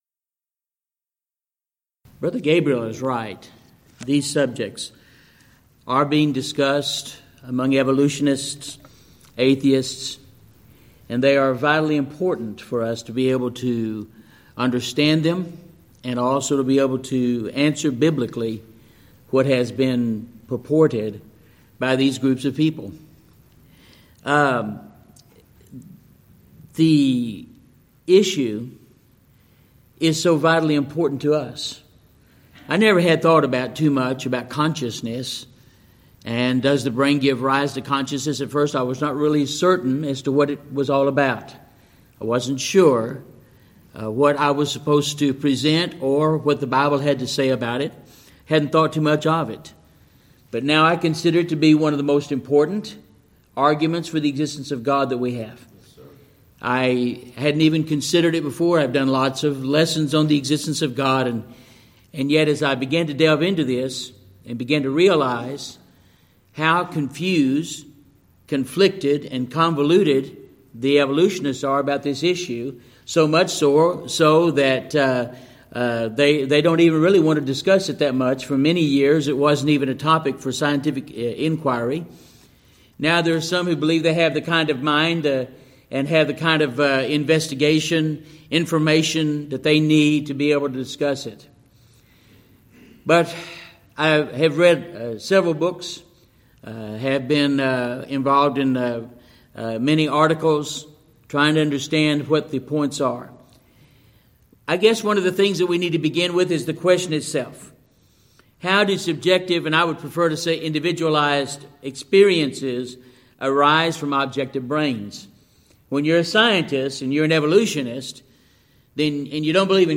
Alternate File Link File Details: Series: Shenandoah Lectures Event: 27th Annual Shenandoah Lectures Theme/Title: The Evolution of Enlightenment: Can Science and Religion Co-Exist?
If you would like to order audio or video copies of this lecture, please contact our office and reference asset: 2014Shenandoah04 Report Problems